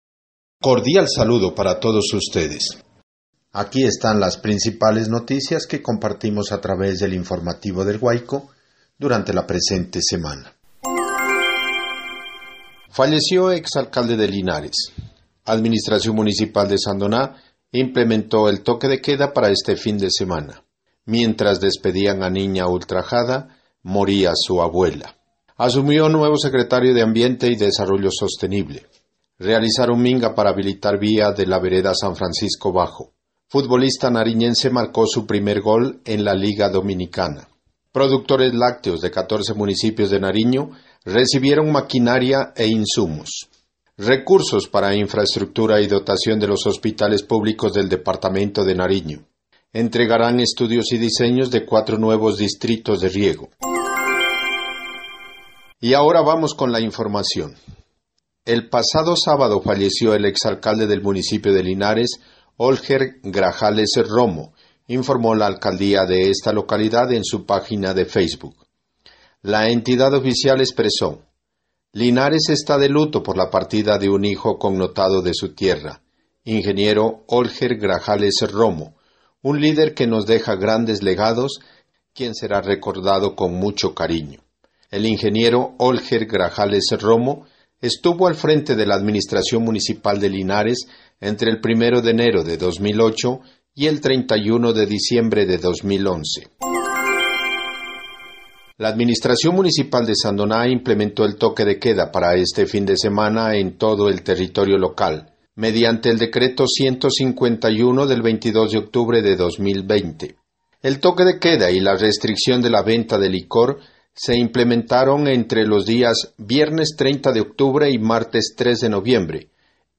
Resumen semanal de noticias (audio 7)